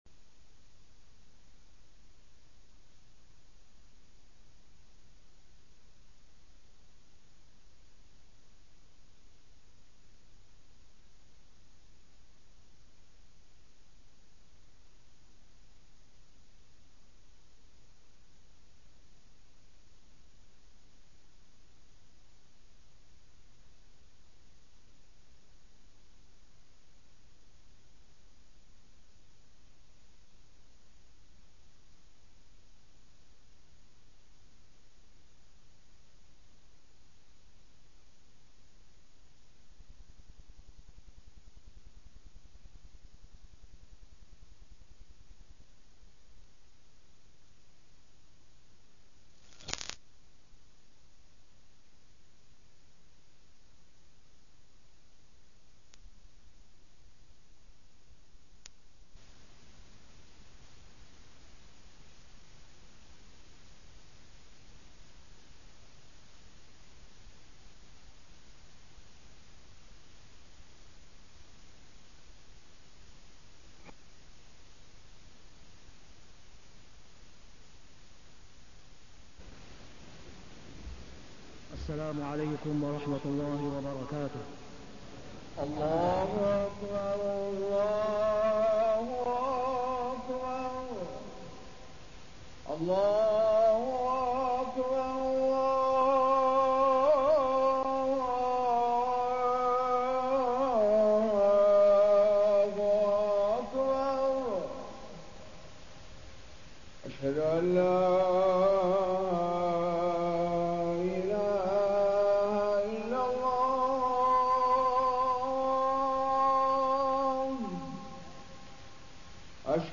تاريخ النشر ٢٤ ذو الحجة ١٤٣٠ هـ المكان: المسجد الحرام الشيخ: فضيلة الشيخ د. أسامة بن عبدالله خياط فضيلة الشيخ د. أسامة بن عبدالله خياط البلاء على قدر الإيمان